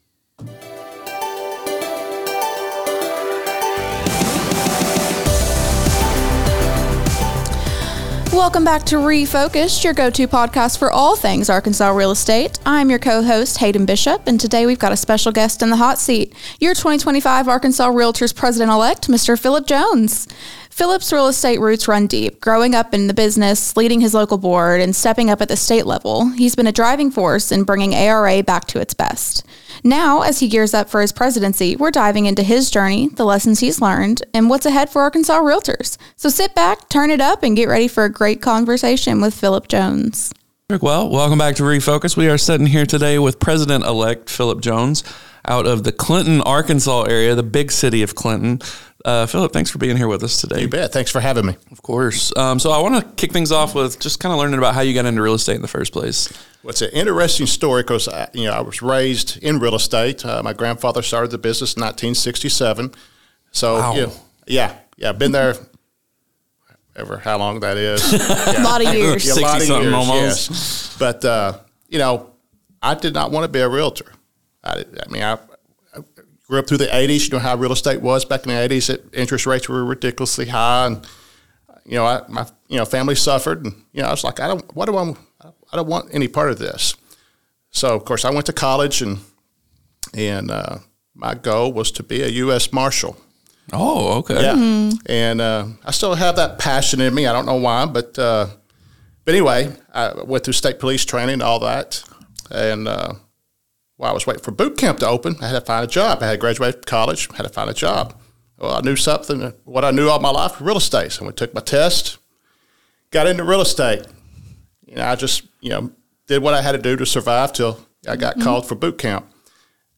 Tune in for an engaging conversation about the impact of strong leadership in shaping the future of Arkansas real estate.